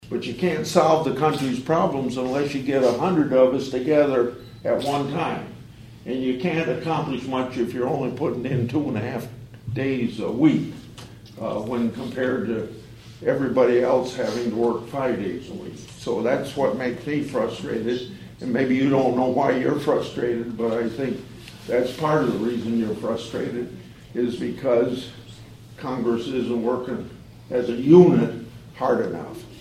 (Atlantic) Senator Chuck Grassley held a town hall meeting at the Iowa Western Community College Cass County campus in Atlantic this (Tuesday) morning.